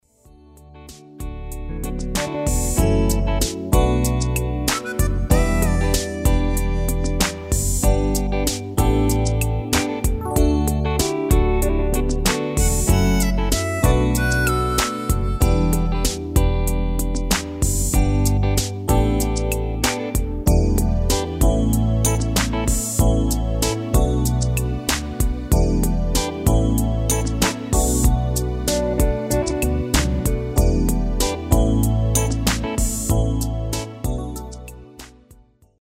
Demo/Koop midifile
Genre: R&B / Soul / Funk
Toonsoort: D
- Vocal harmony tracks
Demo's zijn eigen opnames van onze digitale arrangementen.